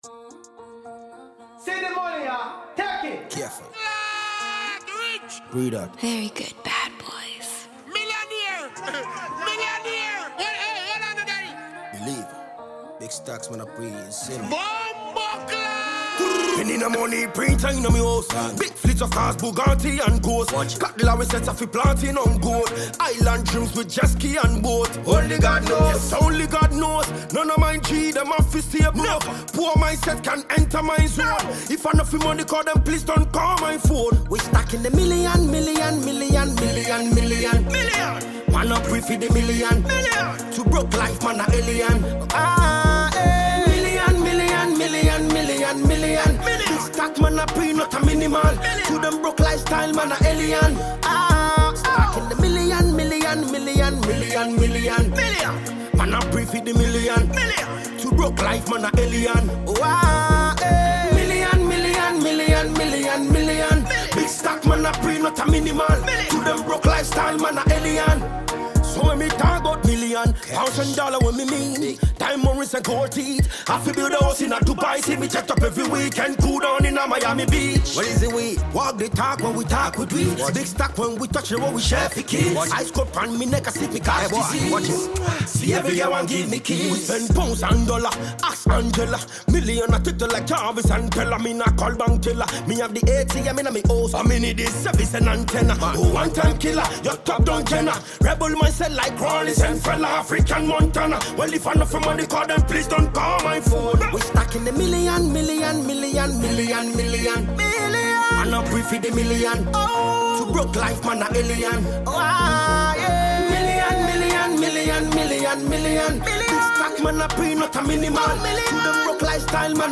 Ghanaian Reggae/Dancehall